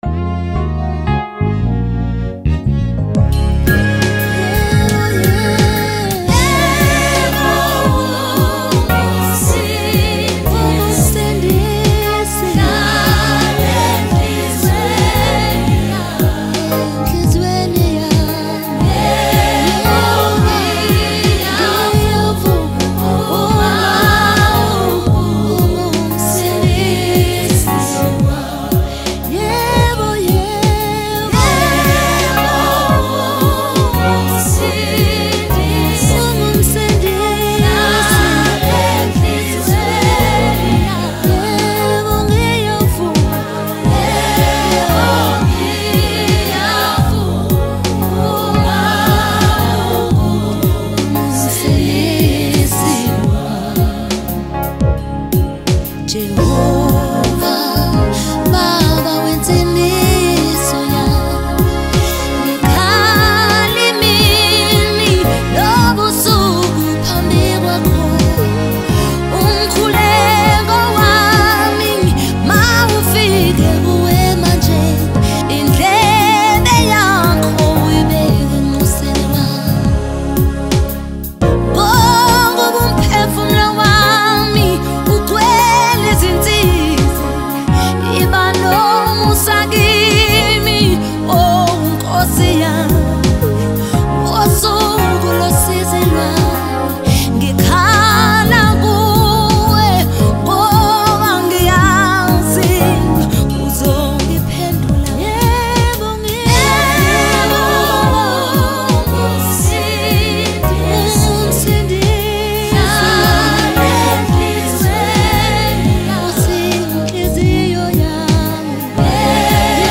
February 4, 2025 Publisher 01 Gospel 0